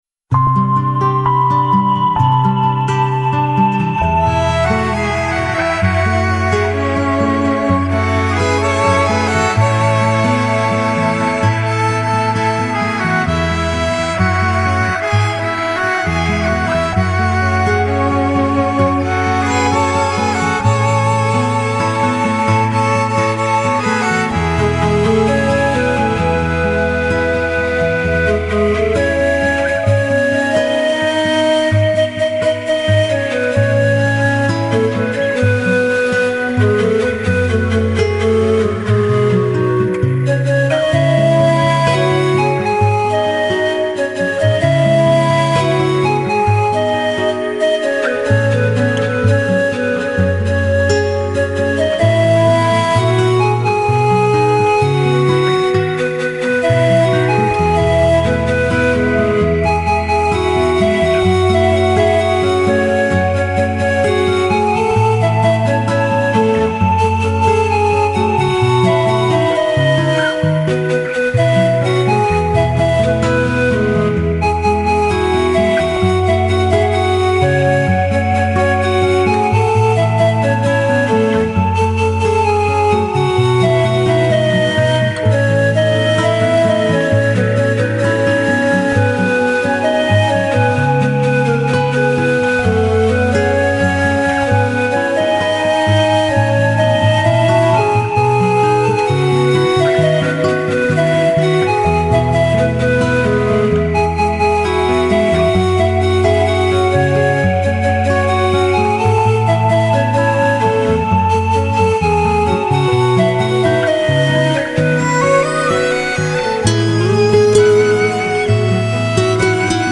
vallenato
acústico
folclore